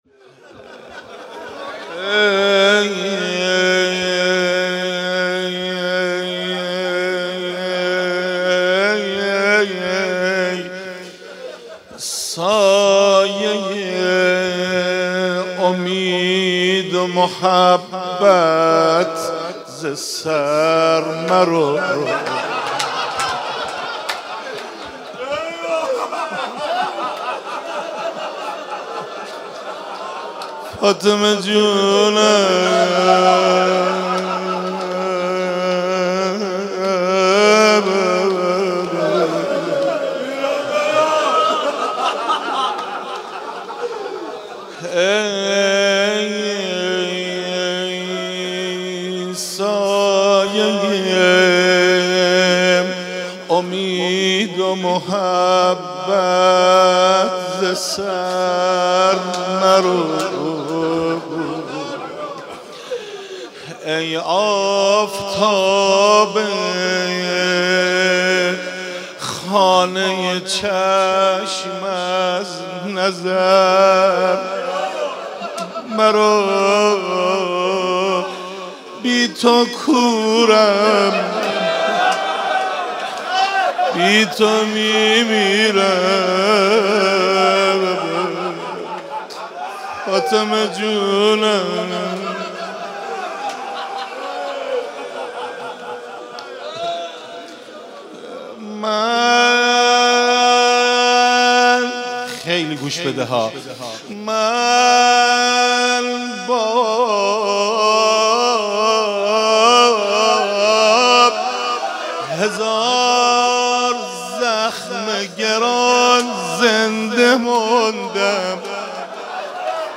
قالب : روضه